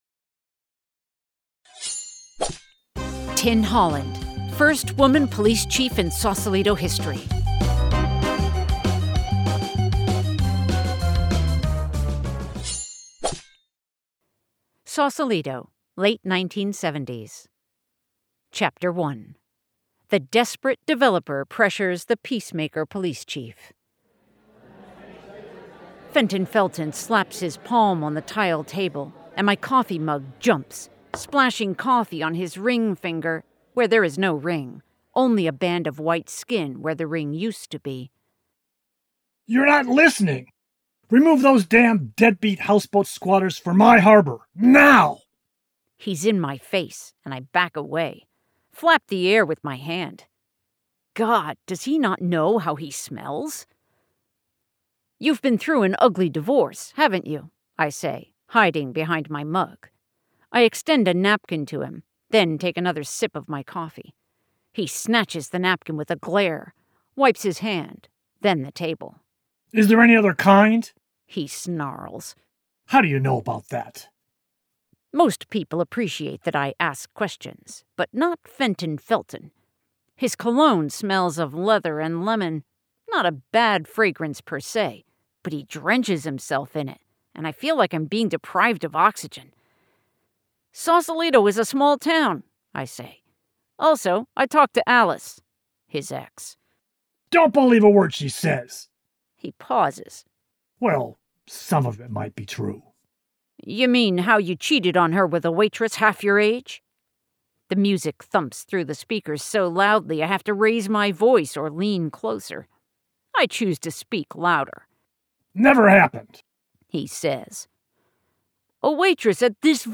Listen to Chapter 1 of ‘Pirates of Sausalito’ Audiobook